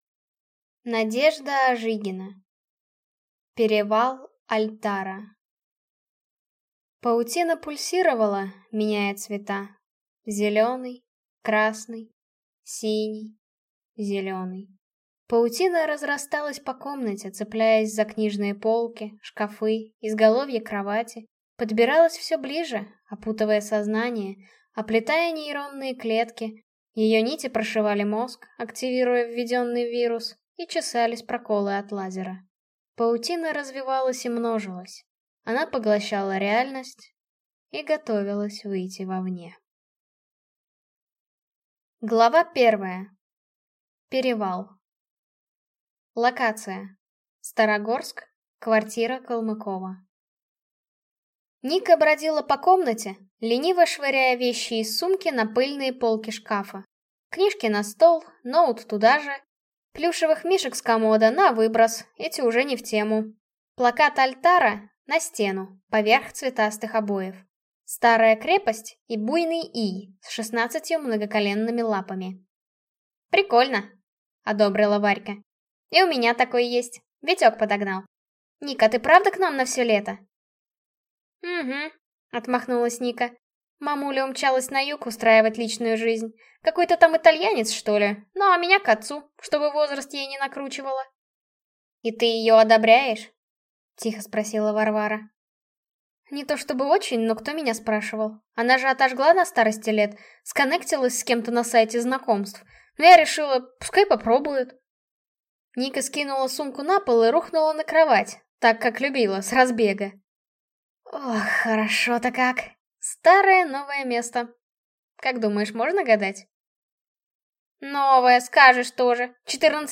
Aудиокнига Перевал Альтара